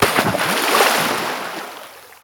Water.wav